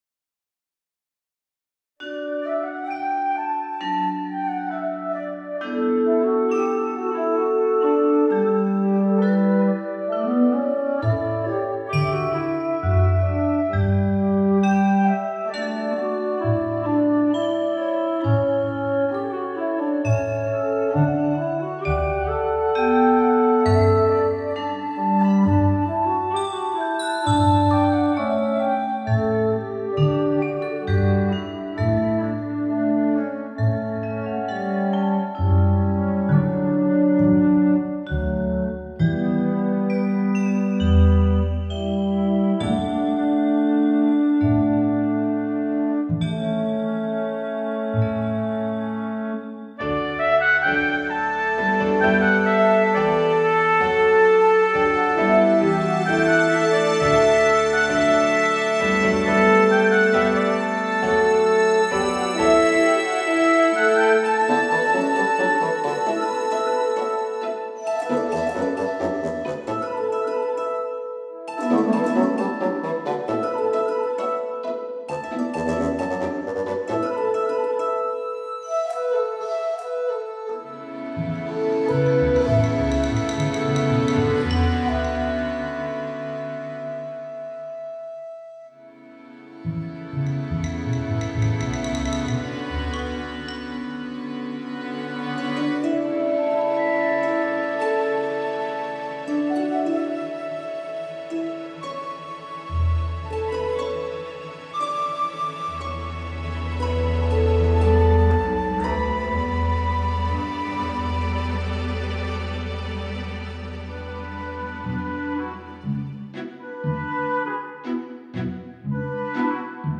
There’s a date happening, but as you can hear it’s a weird date.